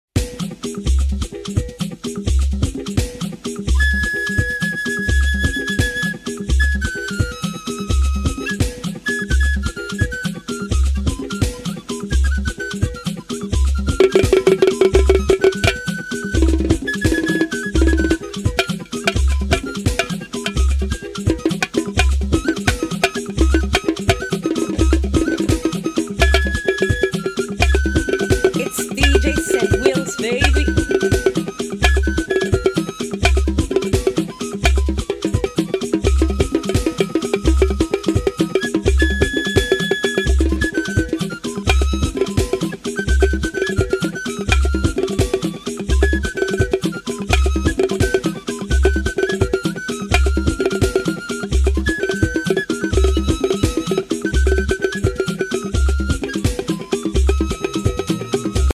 This Igbo instrumental is on another level.